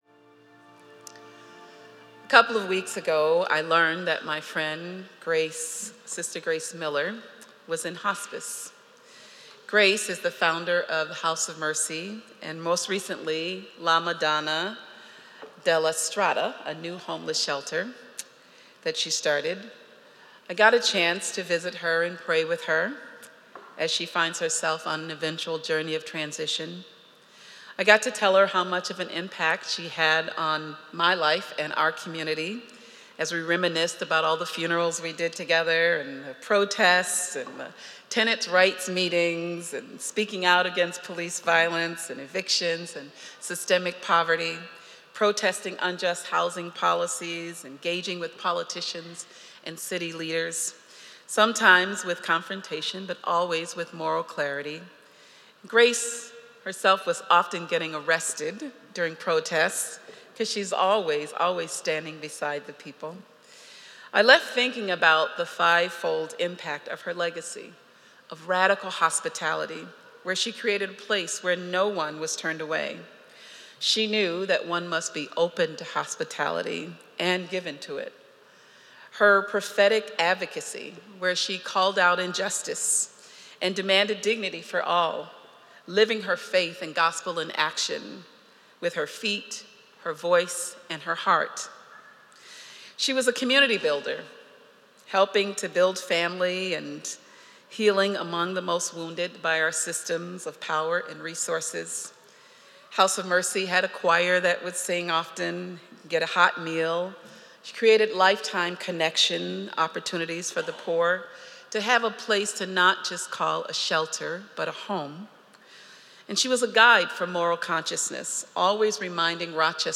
Spiritus-Homily-7.6.25.mp3